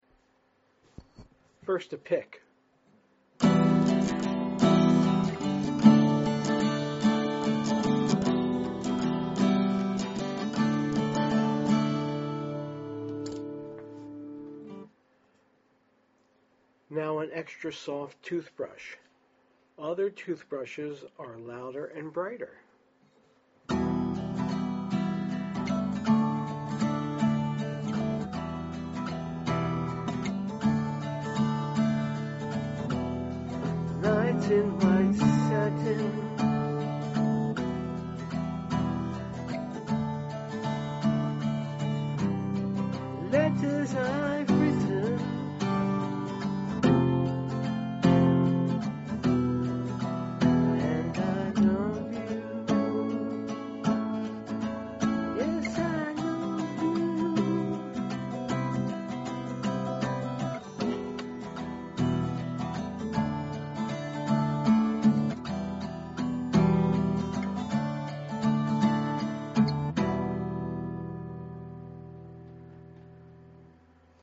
Toothbrush strumming is free, easy and makes every acoustic guitar sound silky smooth.
1. You get a jangly, chorus-like (compressed) shimmer – like it has extra strings!
Audio Demos